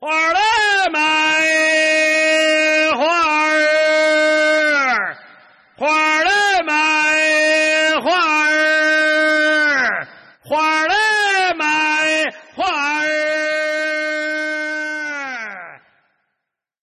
lao_bei_jing_yao_he-nian_hua_er-te_xiao_ren_sheng631.mp3